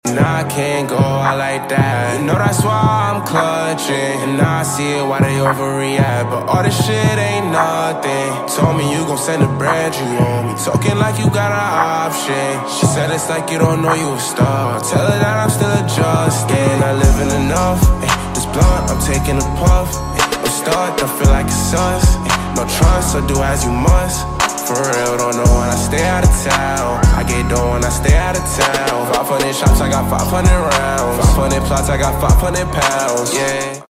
Slowed Version